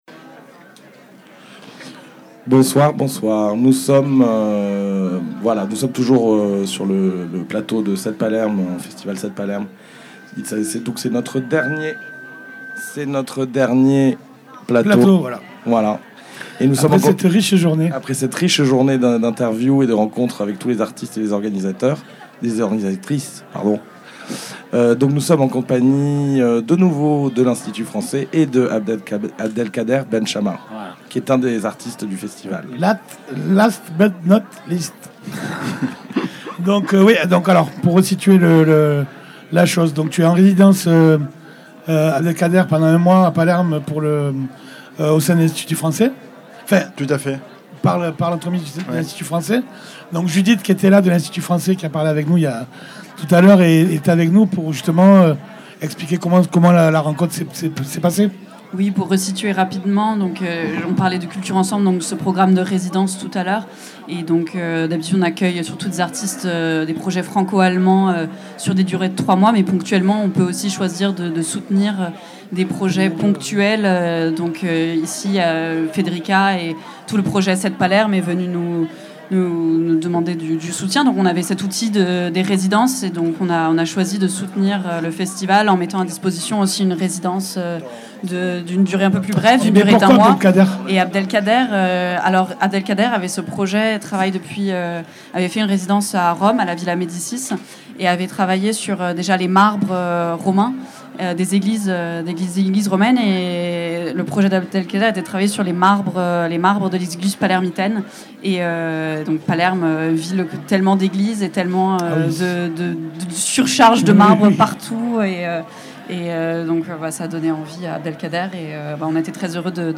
5e Episode (Extrait) du direct diffusé & enregistré le Vendredi 21 Octobre @ Cantieri Culturali alla Zisa.
Attention! Radio Muge ne peut malheureusement pas diffuser l'intégralité du direct, en raison d'un problème technique survenu lors de son enregistrement.